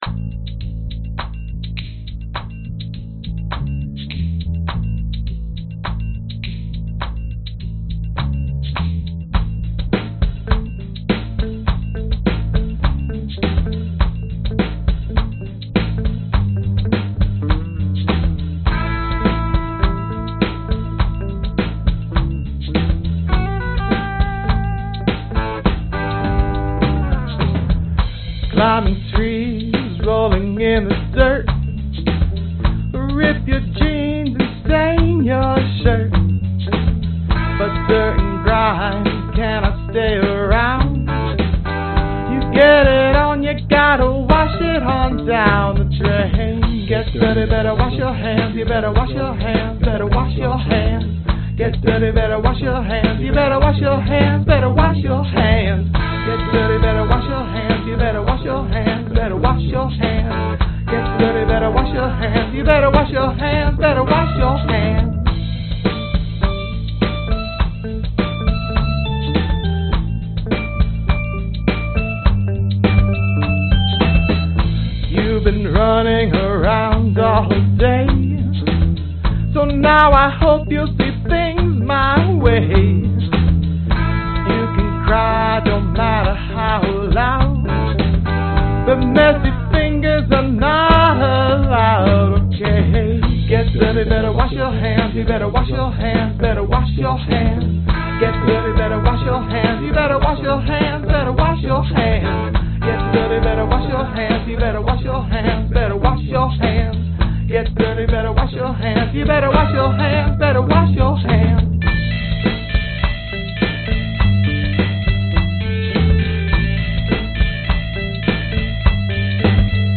Tag: 乐趣 幽默 育儿 男声 清洁 混乱的手指 时髦